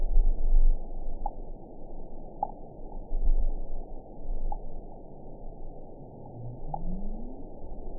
event 912546 date 03/29/22 time 03:41:27 GMT (3 years, 1 month ago) score 8.48 location TSS-AB03 detected by nrw target species NRW annotations +NRW Spectrogram: Frequency (kHz) vs. Time (s) audio not available .wav